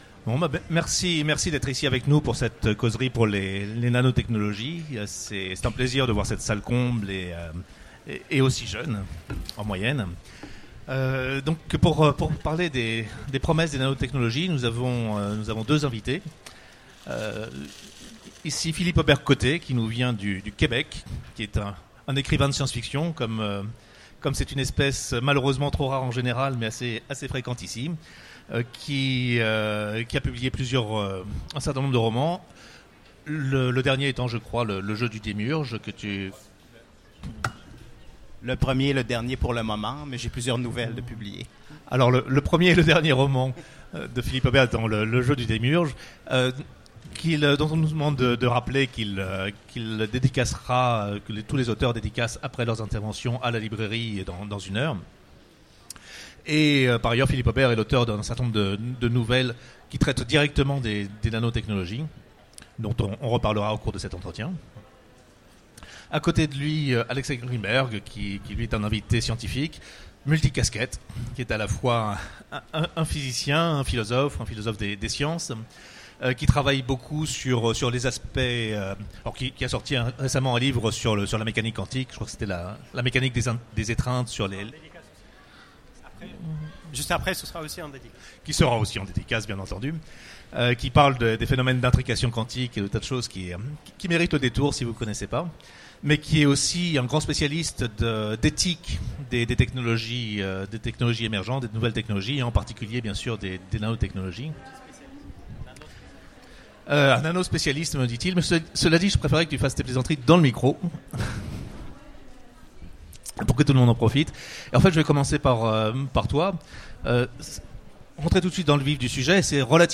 Utopiales 2016 : Conférence Les promesses des nanotechnologies